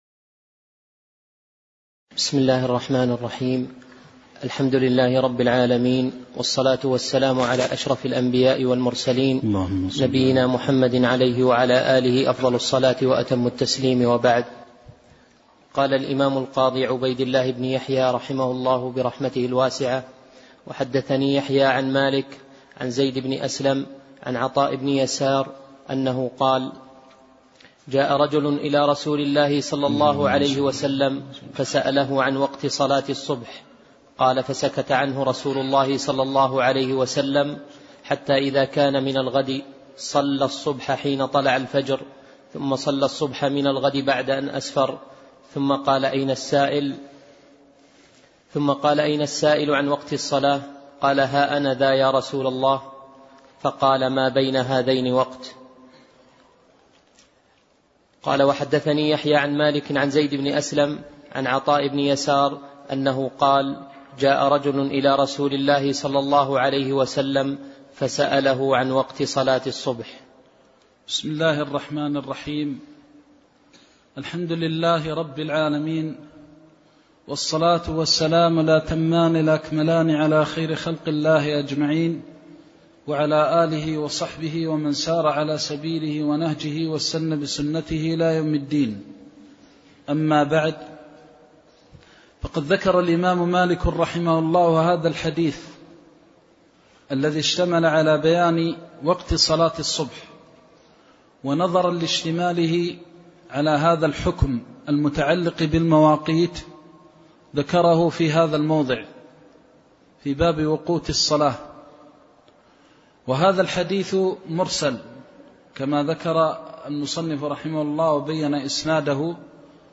الدرس الرابع من قول المصنف رحمه الله : حديث عطاء بن يسار رضي الله عنه عن وقت صلاة الصبح إلى قول المصنف رحمه الله :باب جامع الوقوت